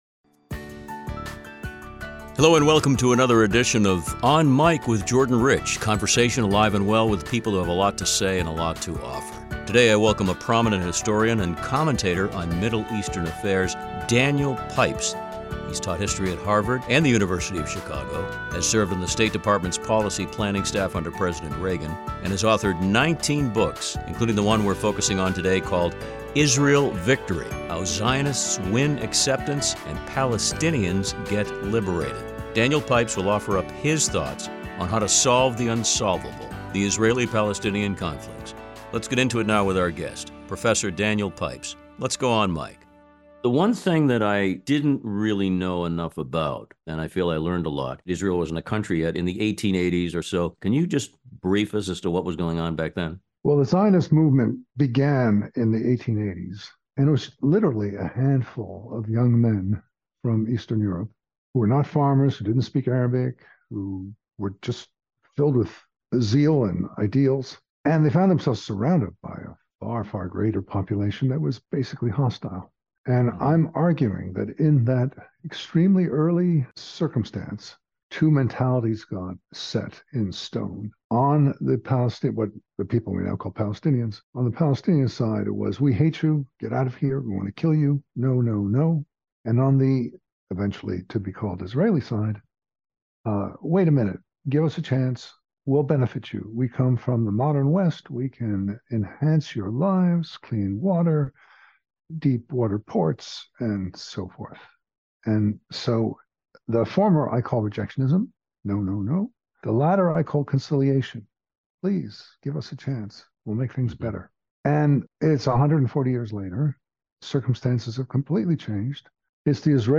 Interviews with Daniel Pipes